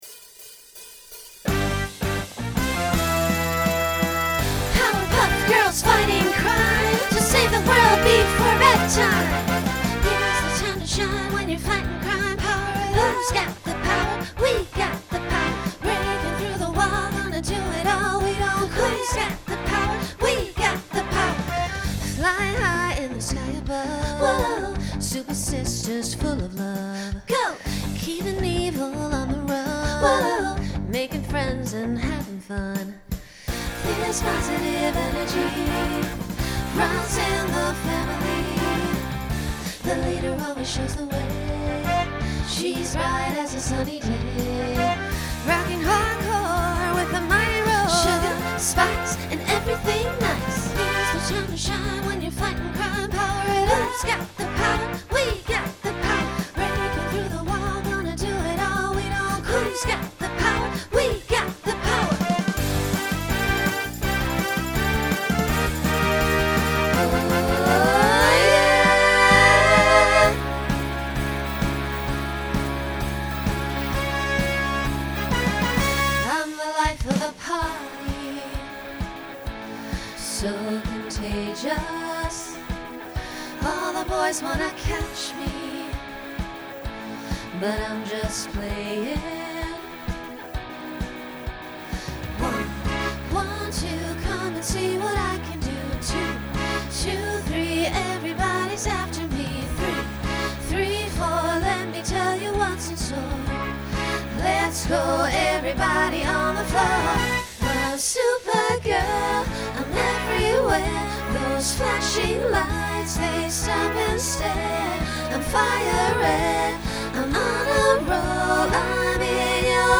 Genre Pop/Dance , Rock Instrumental combo
Voicing SSA